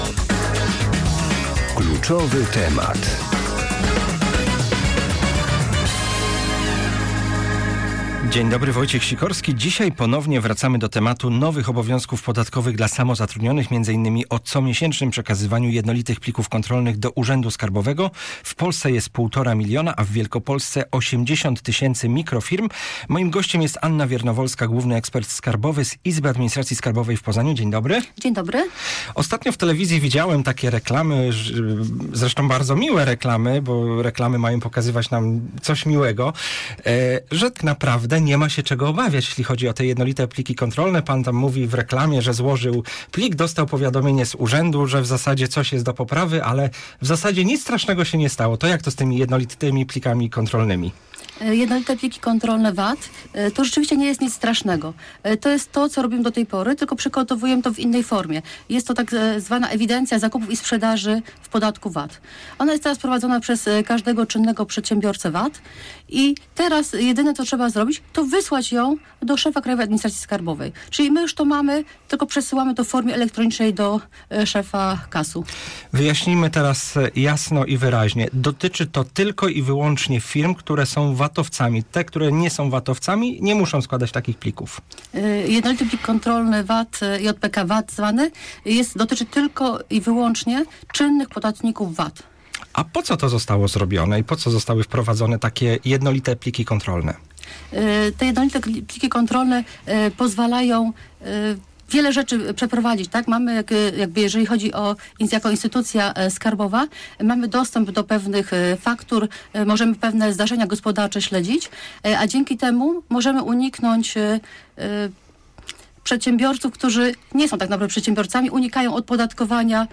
Po audycji "Kluczowy temat" nasi słuchacze mogli przez godzinę zadawać pytania do eksperta skarbowego.